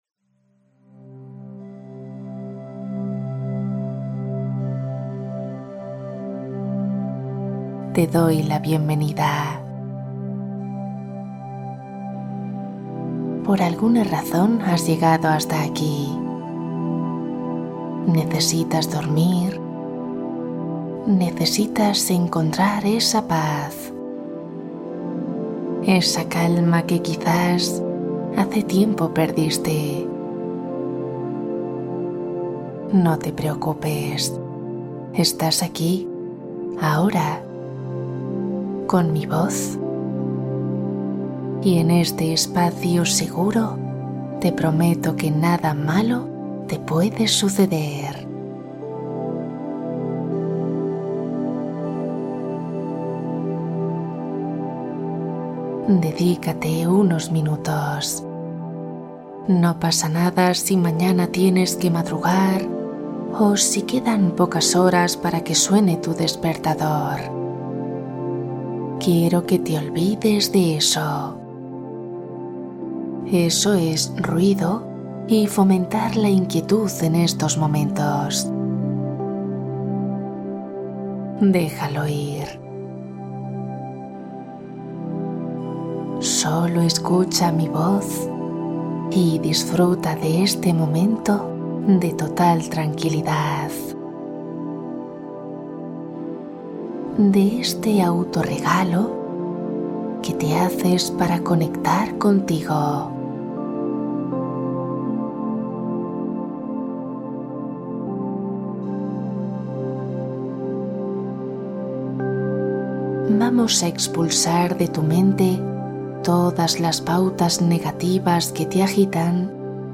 Sueño inmediato Meditación guiada para dormir profundo y vencer el insomnio